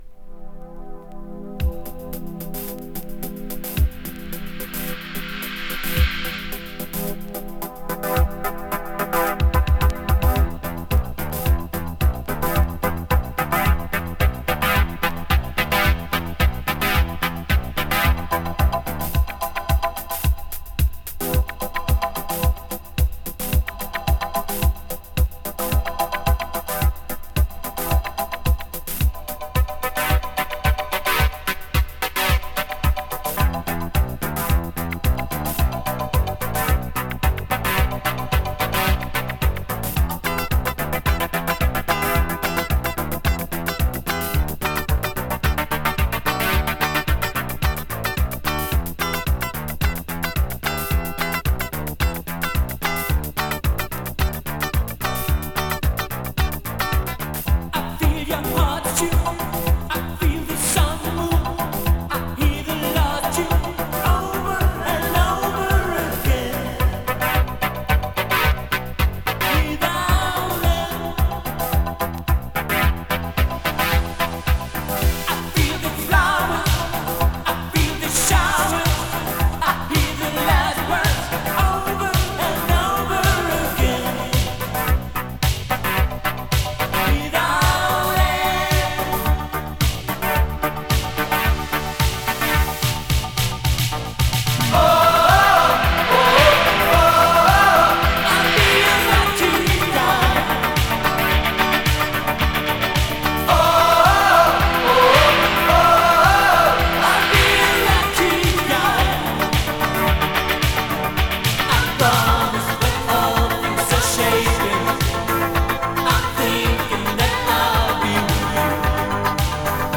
【NEW WAVE】 【GERMANY】
German New Wave Disco!
A melancholy new wave disco with a cosmic sound!